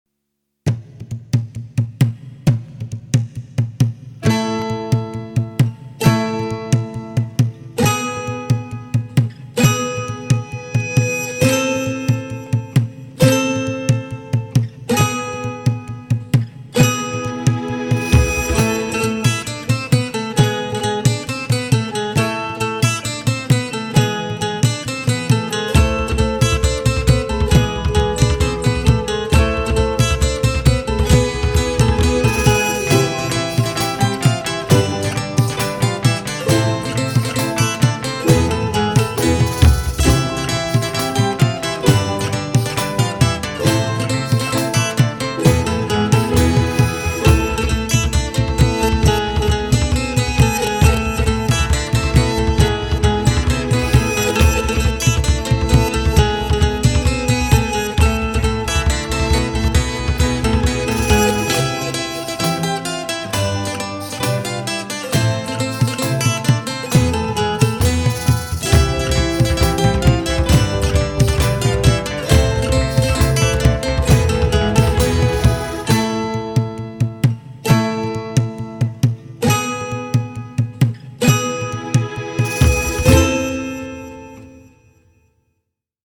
Theatre